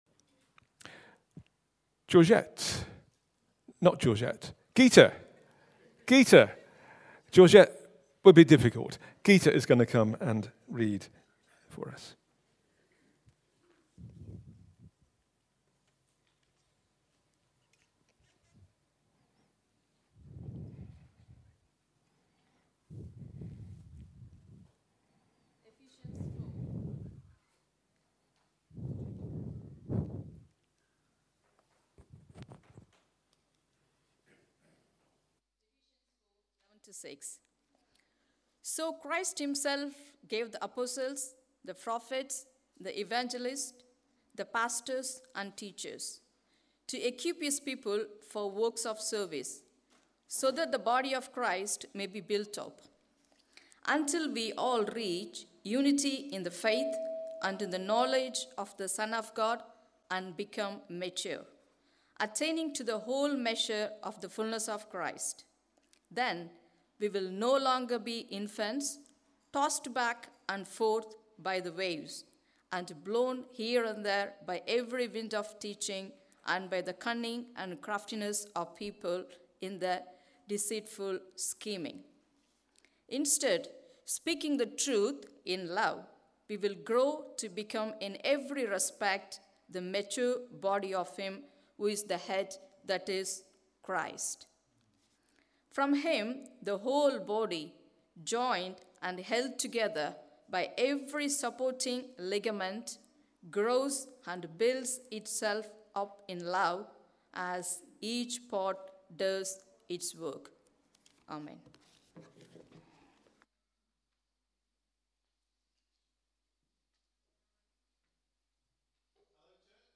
A talk from the series "Healthy Church Culture."